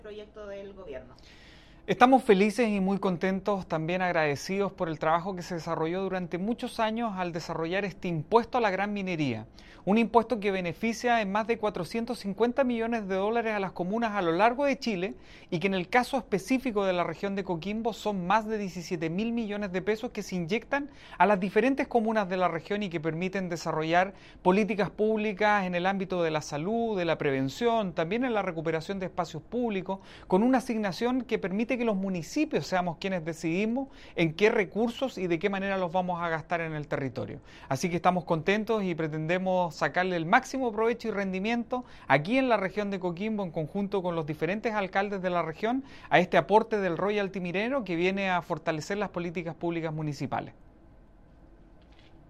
Alí Manouchehri, Alcalde de Coquimbo y Presidente de la Asociación de Municipalidades de la Región, dijo que
ROYALTY-MINERO-Ali-Manouchehri-Alcalde-Coquimbo.mp3